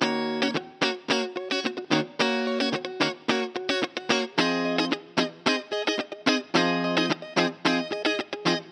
03 Guitar PT1.wav